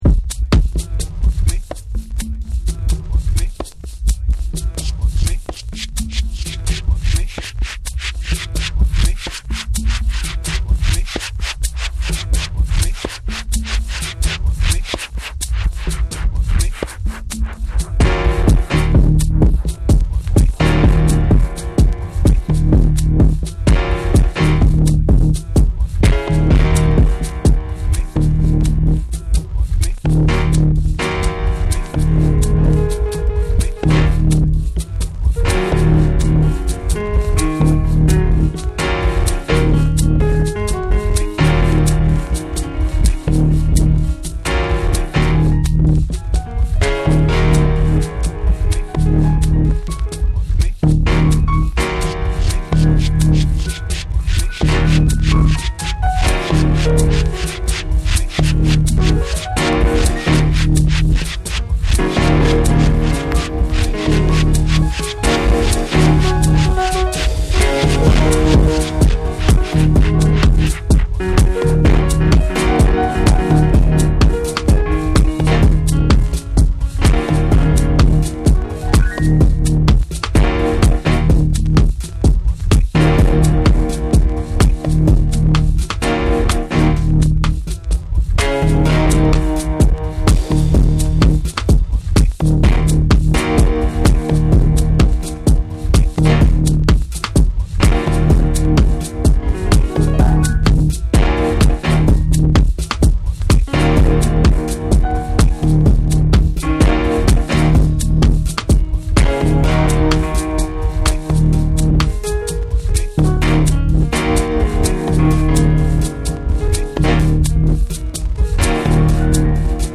じわじわとグルーヴを積み上げるミニマルなトラックの上を、ストイックな鍵盤フレーズが淡々と巡る
TECHNO & HOUSE / ORGANIC GROOVE